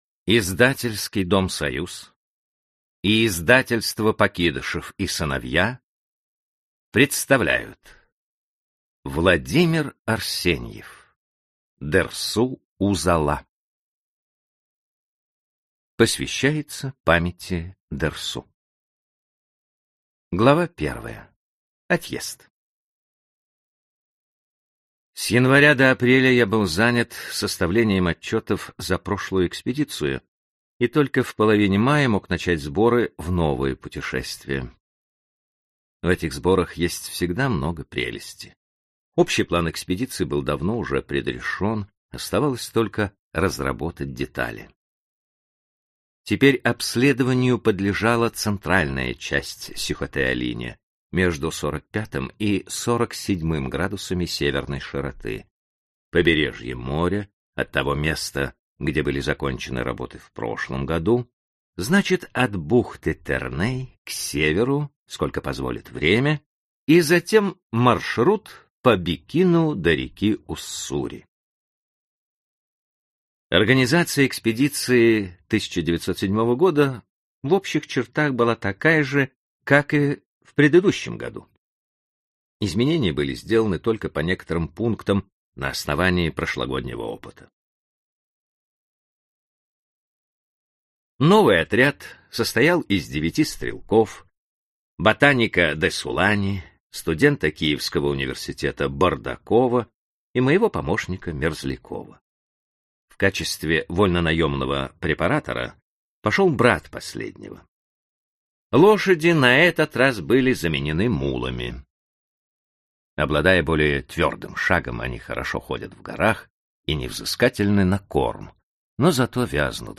Аудиокнига Дерсу Узала | Библиотека аудиокниг